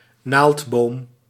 Ääntäminen
Synonyymit résineux Ääntäminen France: IPA: [kɔ.ni.fɛʁ] Haettu sana löytyi näillä lähdekielillä: ranska Käännös Ääninäyte 1. naaldboom {m} 2. conifeer {m} Suku: m .